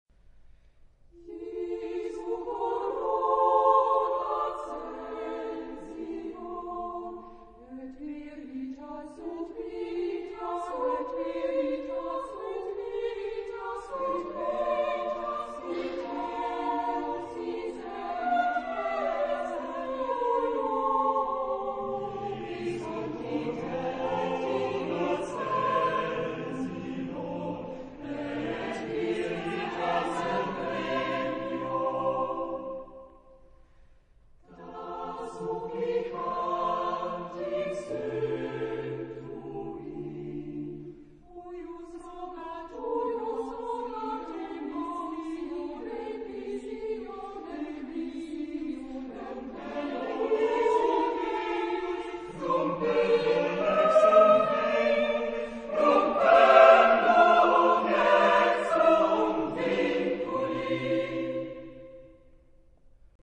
Genre-Style-Form: Motet ; Sacred
Type of Choir: SAATBB  (6 mixed voices )
Tonality: F major
sung by Bel Canto Kammerchor München
Discographic ref. : 7. Deutscher Chorwettbewerb 2006 Kiel